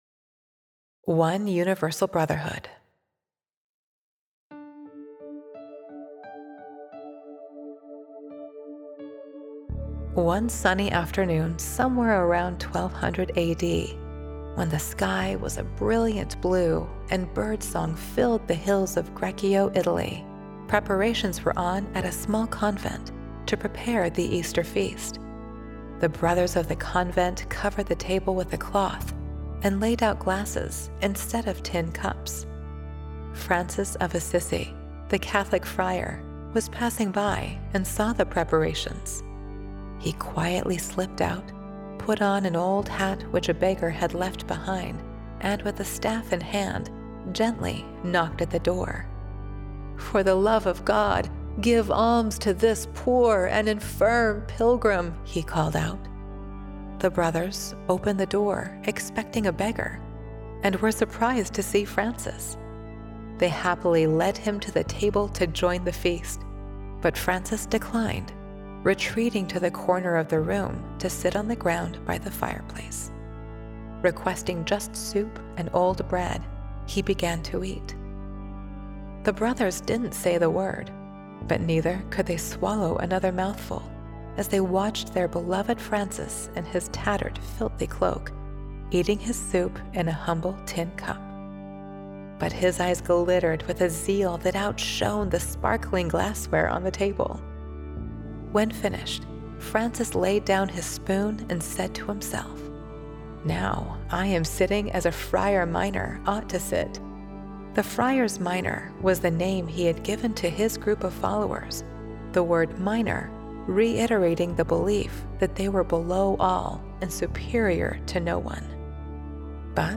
One Universal Brotherhood (Audio Story)